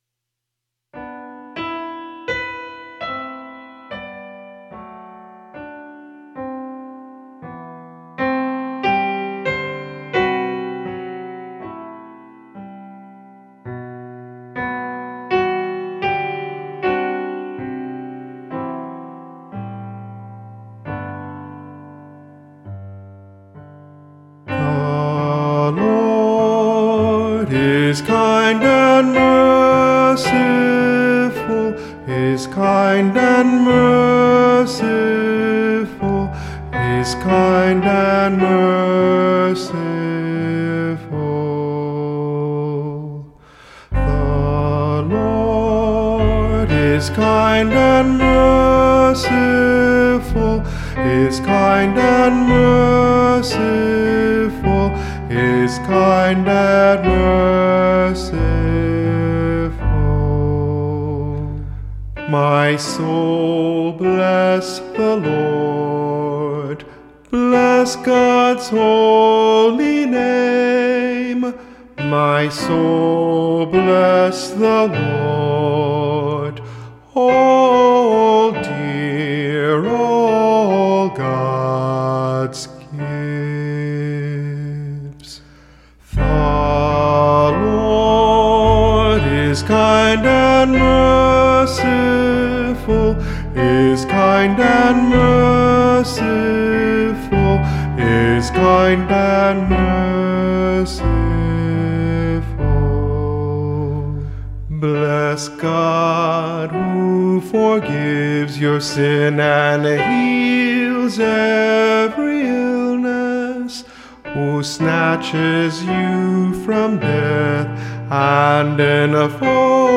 Responsorial Psalms